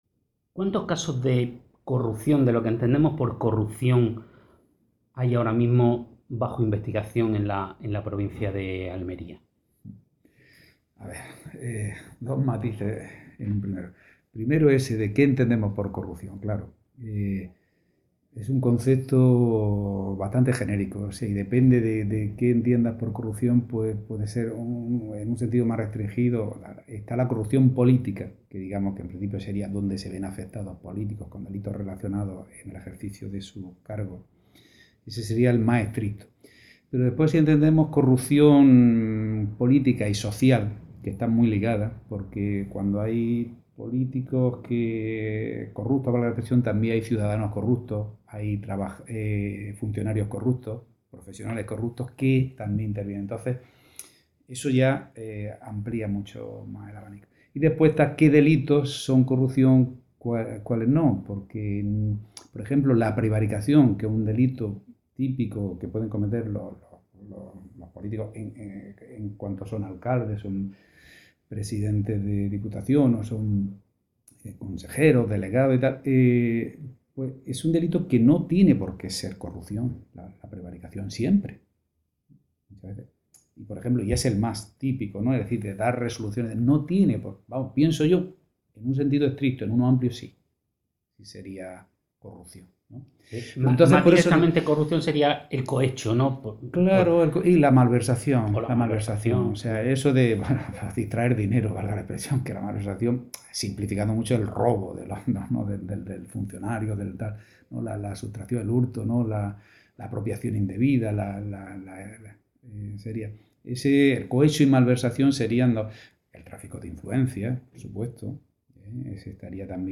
Planteamos la entrevista con el Fiscal Jefe de la Audiencia Provincial de Almería, Antonio Pérez Gallegos, en relación a un único tema, el de la corrupción.